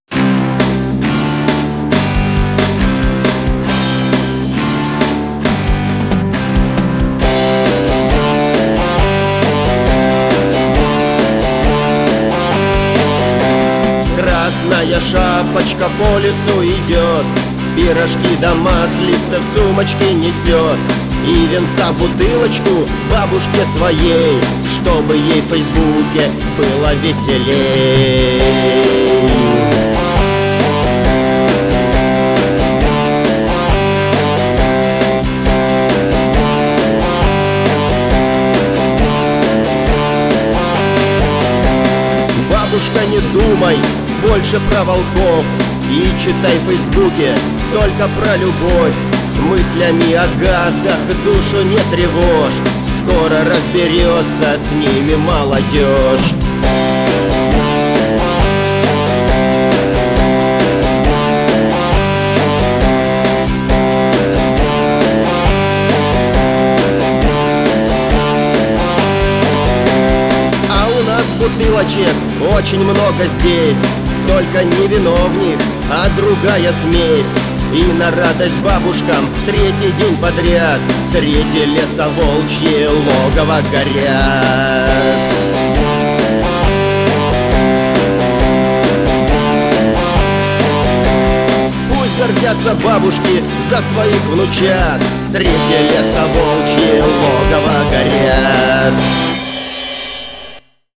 политический панк-рок